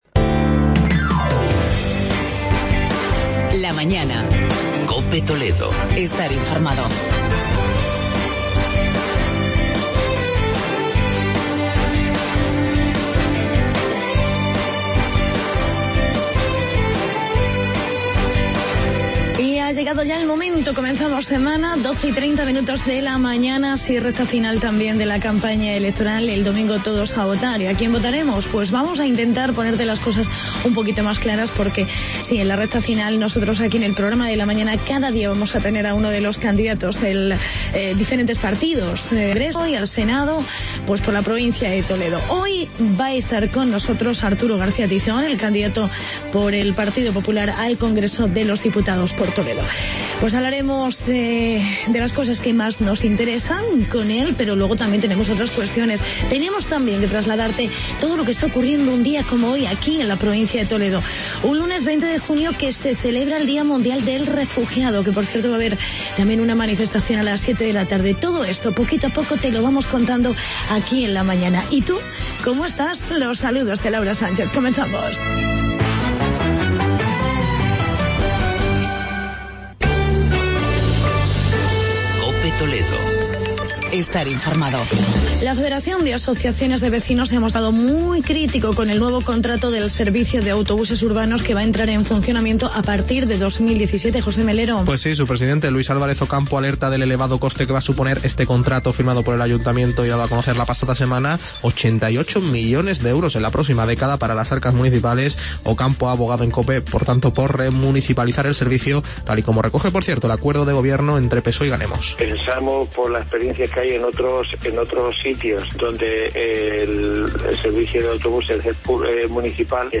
Entrevista con Arturo García Tizón, candidato popular por Toledo al Congreso.